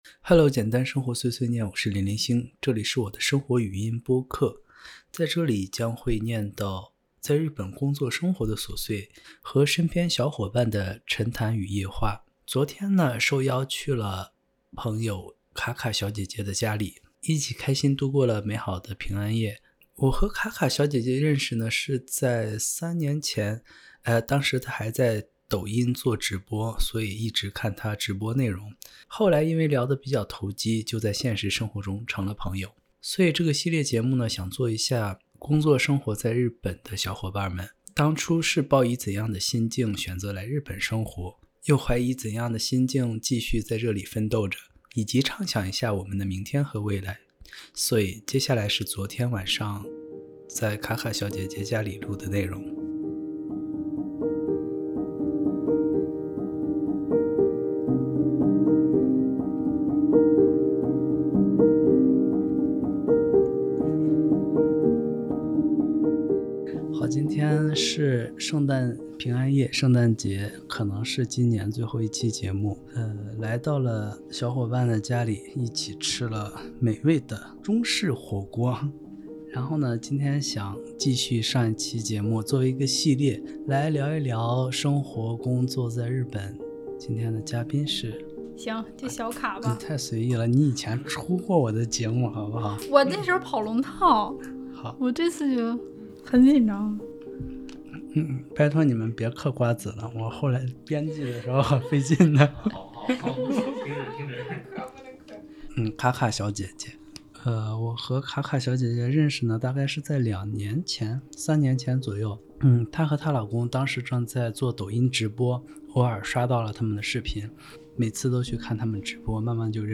2022年平安夜，和曾在抖音直播相遇的小姐姐语聊。